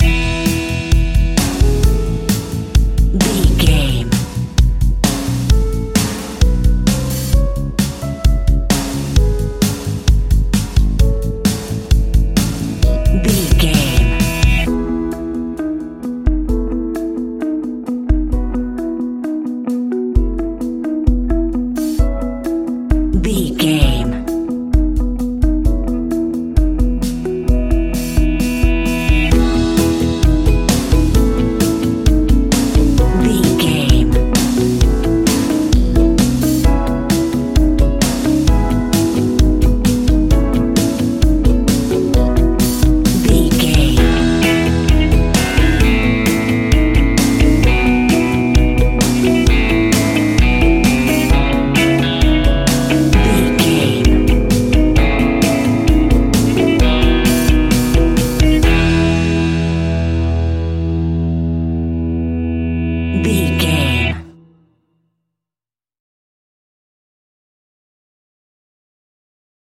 Uplifting
Ionian/Major
pop rock
indie pop
fun
energetic
instrumentals
guitars
bass
drums
piano
organ